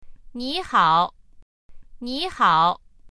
nihao.mp3